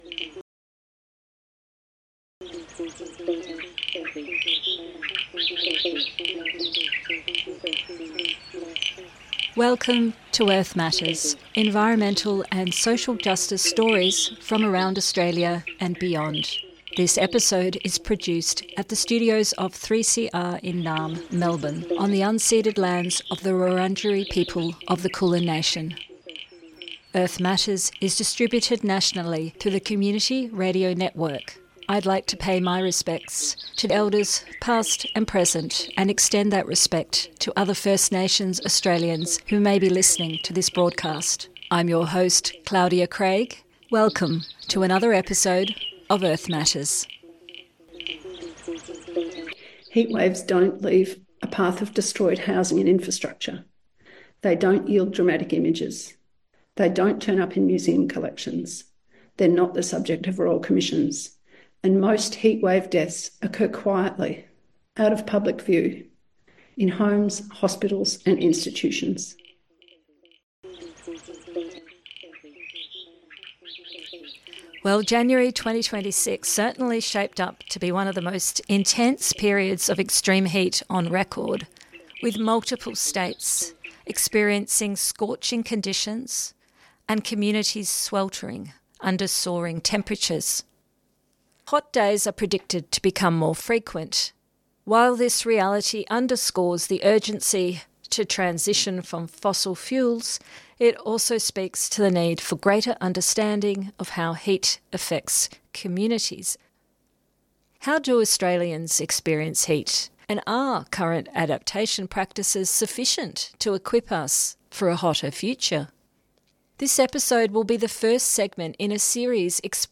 We also hear archival audio of Dr Vandana Shiva from the year 2000. She was speaking at the 'Global Capitalism, Local Responses' seminar which was hosted by RMIT, on the eve of the World Economic Forum’s Asia Pacific Summit.